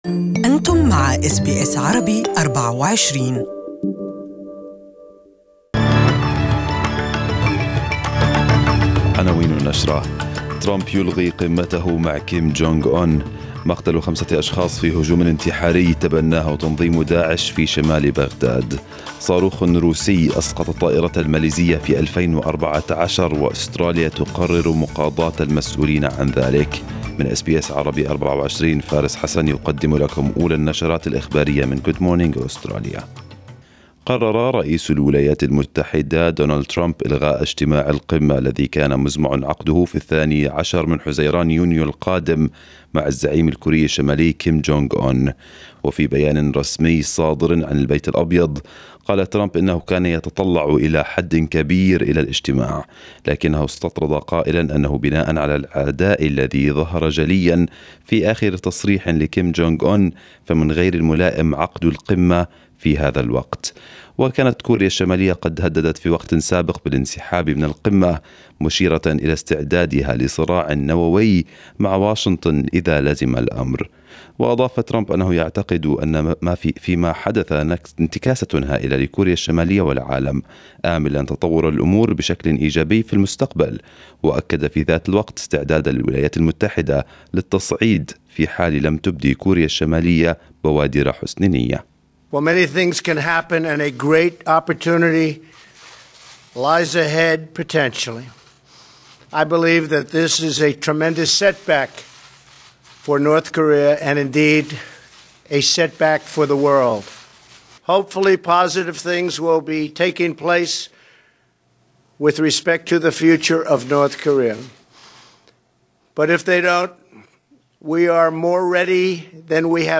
Arabic News Bulletin